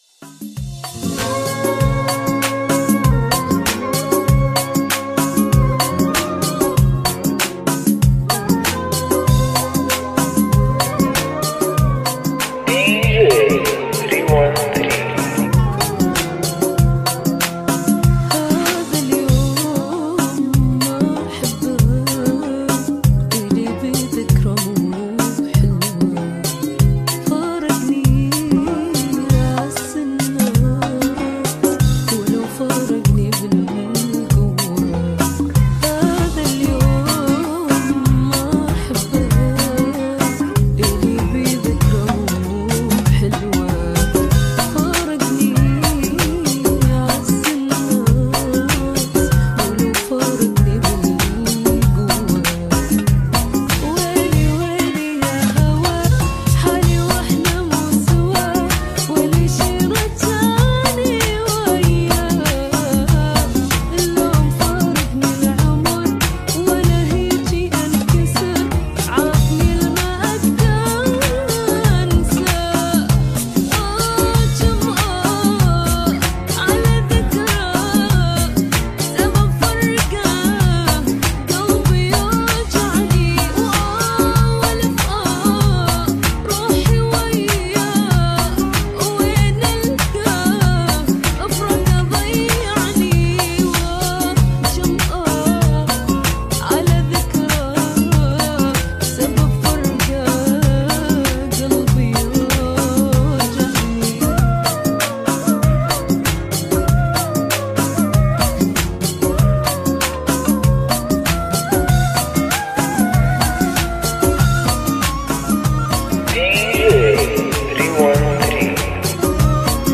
ريمگـس